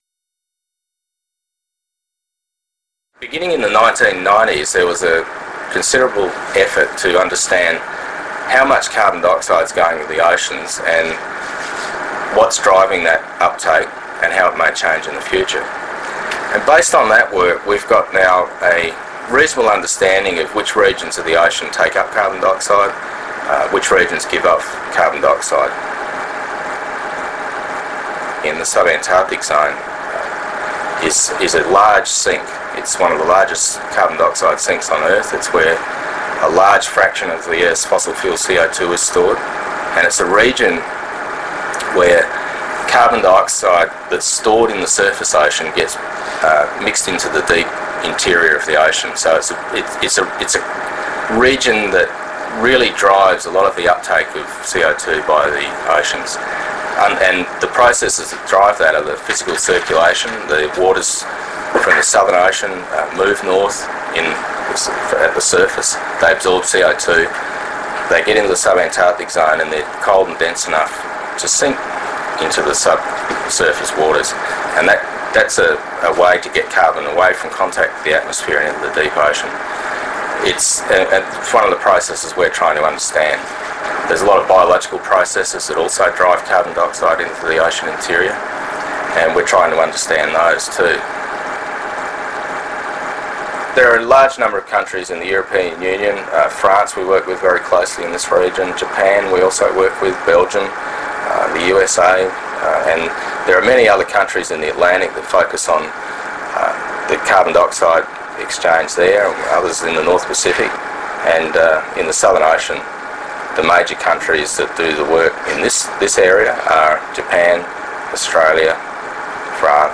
Scientist Interviews